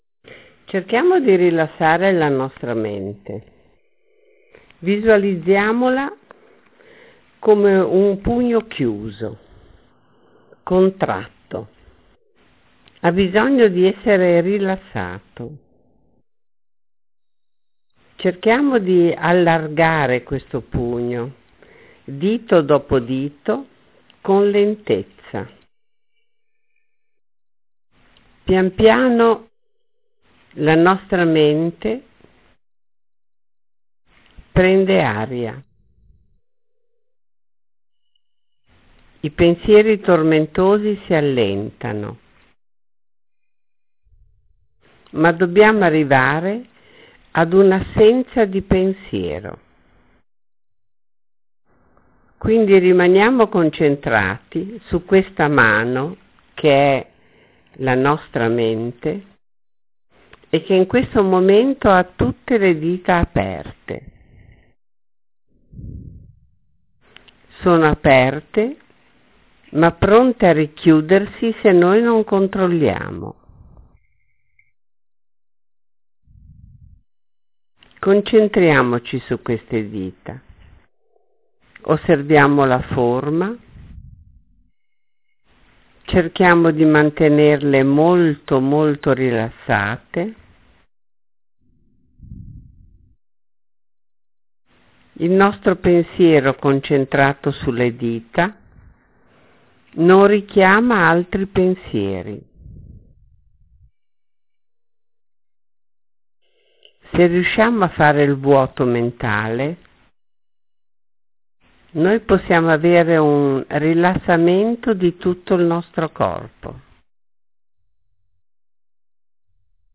Rilassiamo la Mente – meditazione
Rilassiamo-la-Mente-meditazione.mp3